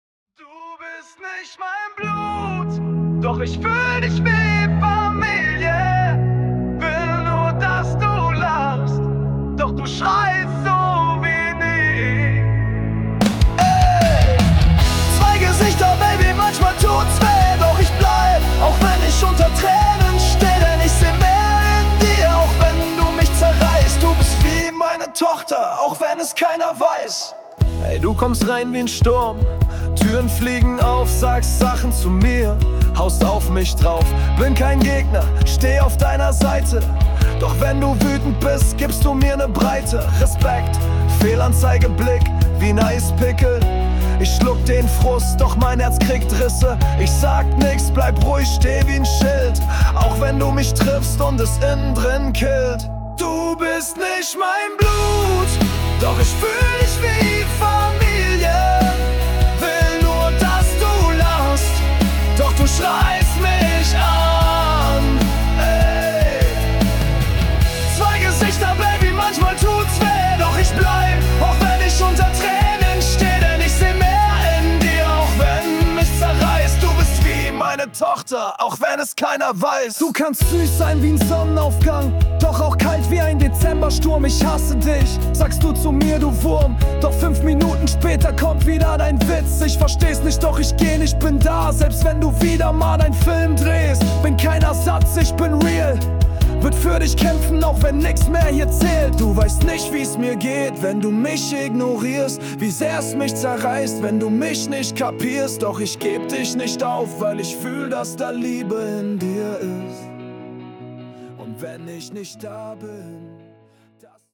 Rockballade